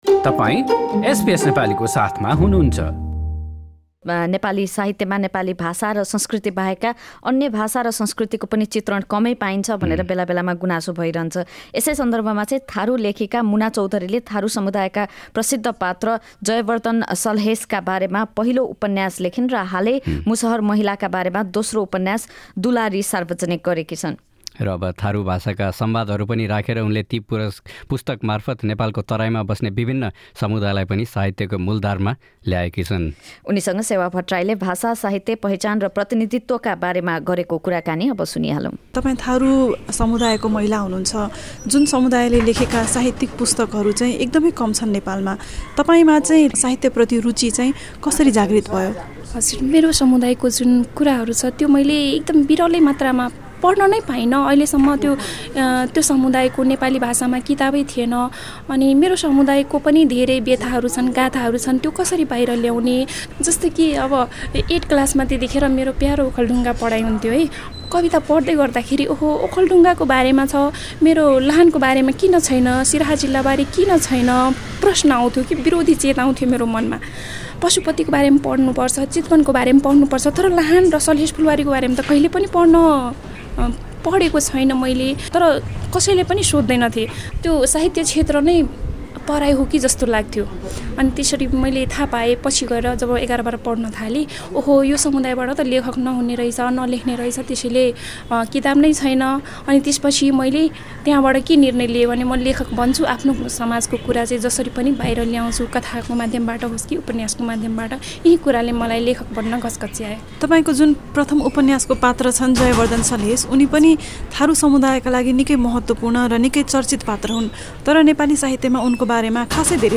उनीसँग भाषा, साहित्य, पहिचान र प्रतिनिधित्वका बारेमा गरेको कुराकानी : तपाईँ थारु समुदायको महिला हुनुहुन्छ, जुन समुदायका साहित्यिक लेखक एकदम कम छन् नेपालमा।